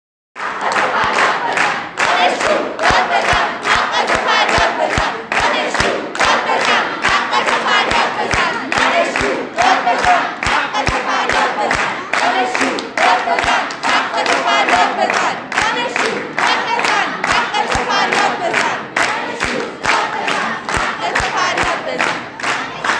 تجمع دانشجویان دانشکده روانشناسی دانشگاه تهران در امتداد اعتراضات سراسری با شعارهای "دانشجو داد بزن، حقتو فریاد بزن" و "آزادی